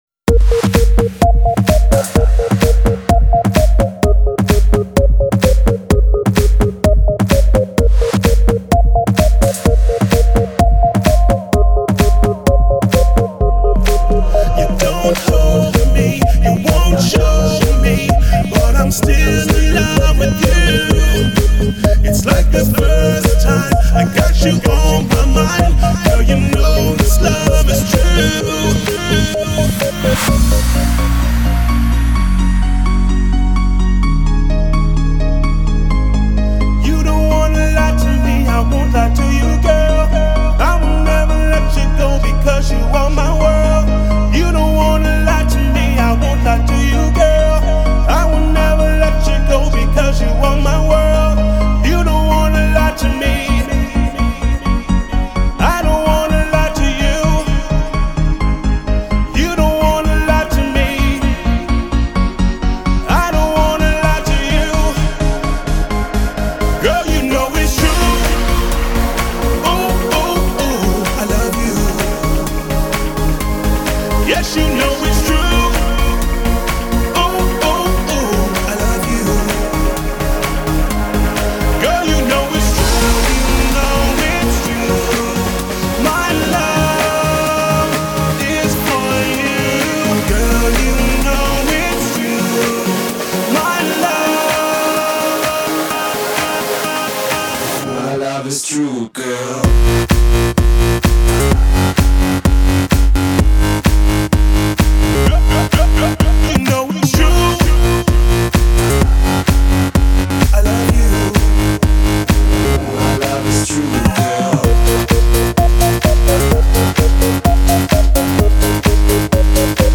Жанр-club, dj, клубная